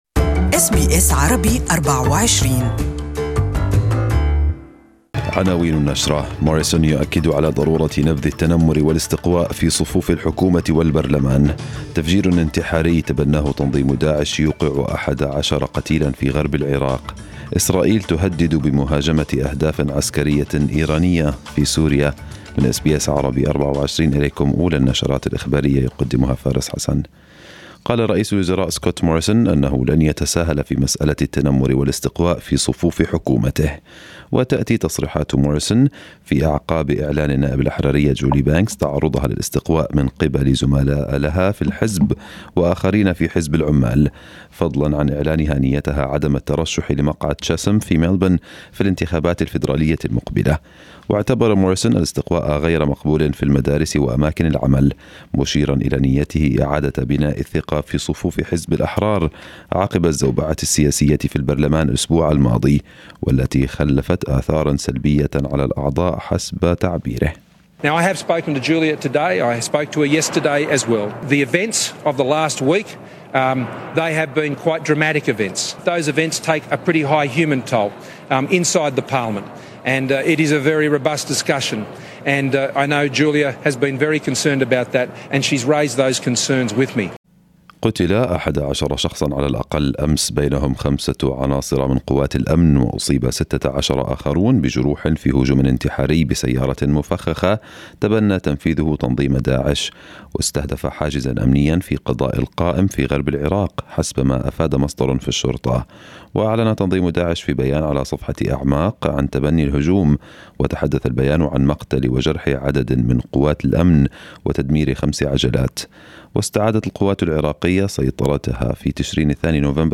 Listen to the full news bulletin in Arabic above.